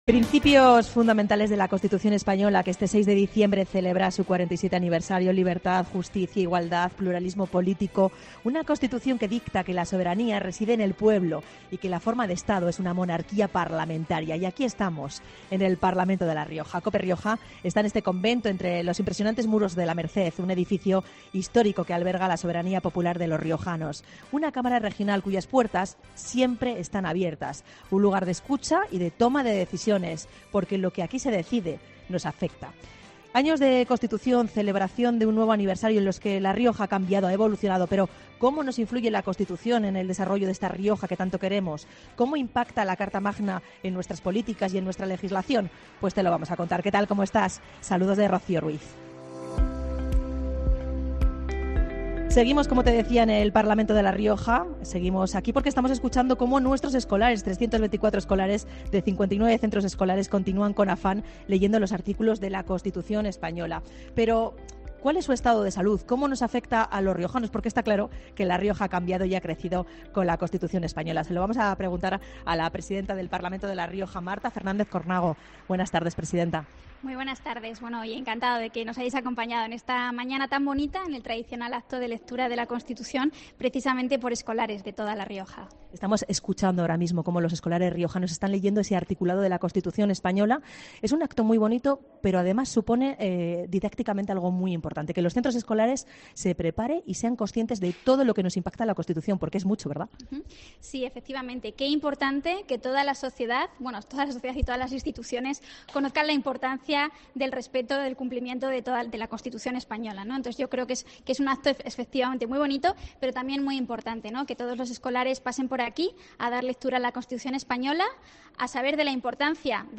La presidenta del Parlamento de La Rioja reivindica el respeto a la Carta Magna en el acto de lectura escolar con motivo de su 47 aniversario